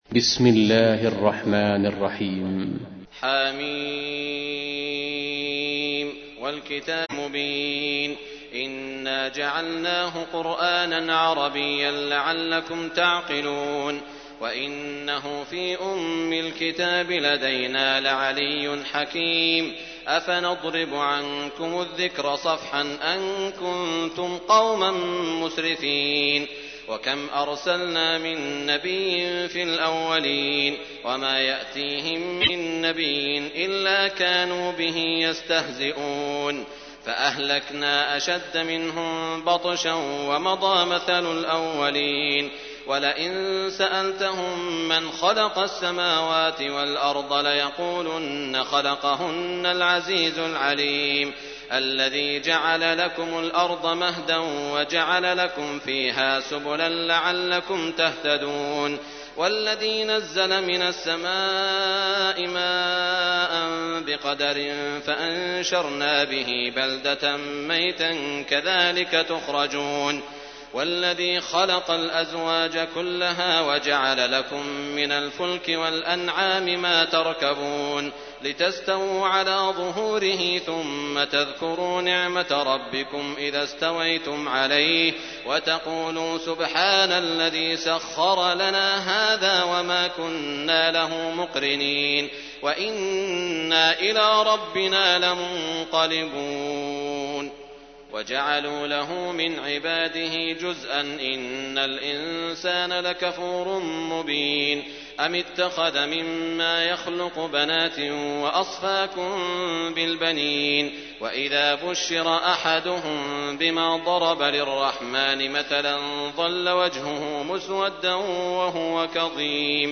تحميل : 43. سورة الزخرف / القارئ سعود الشريم / القرآن الكريم / موقع يا حسين